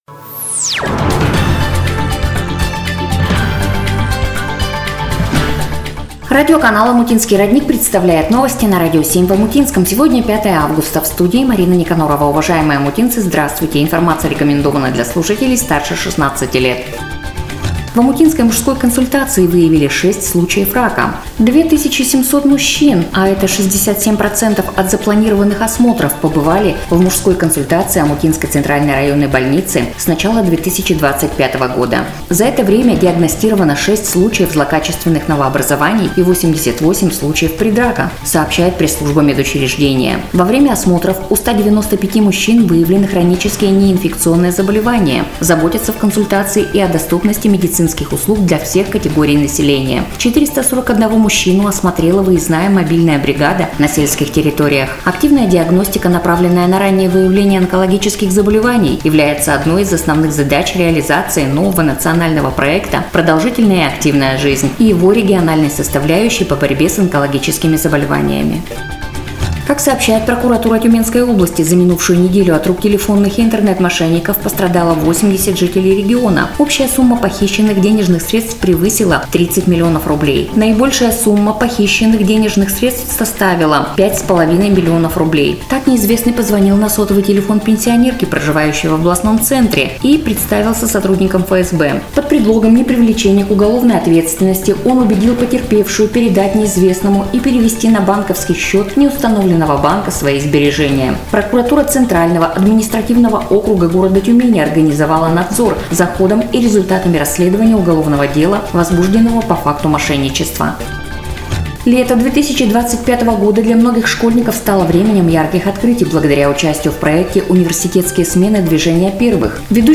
NOVOSTI-05.08.25.mp3